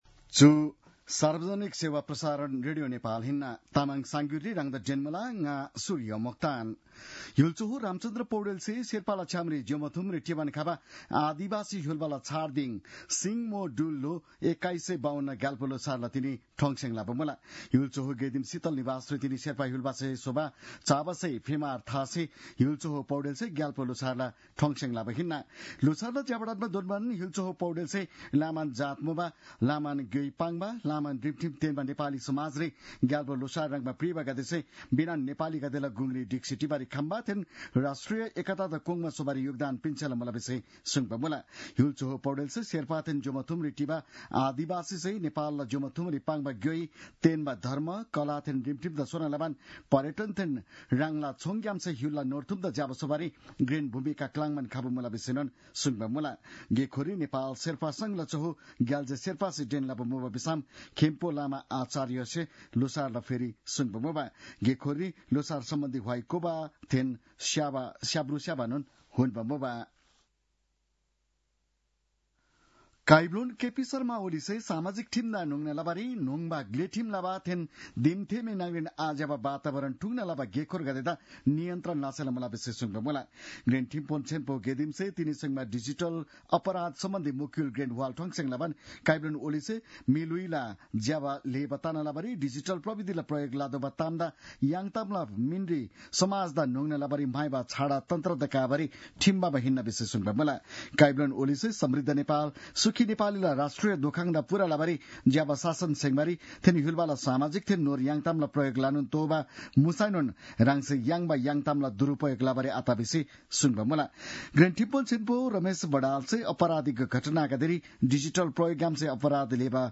तामाङ भाषाको समाचार : १६ फागुन , २०८१